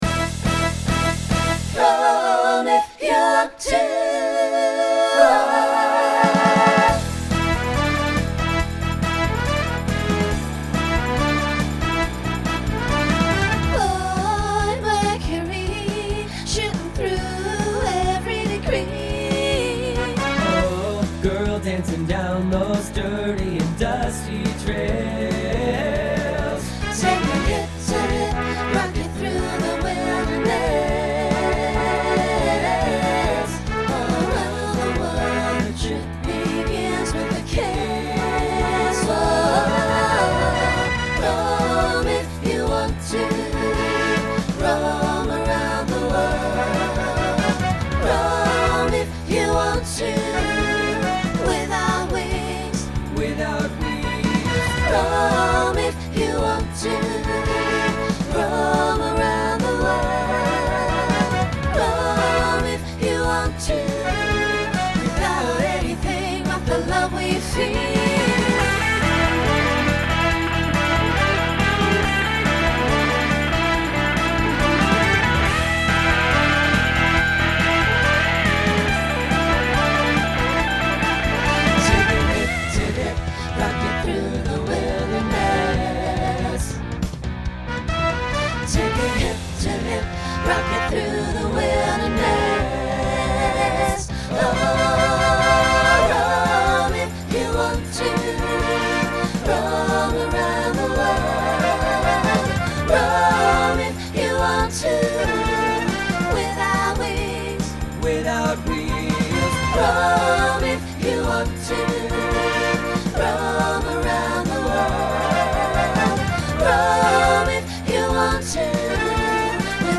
Genre Rock Instrumental combo
Quick Hitter Voicing SATB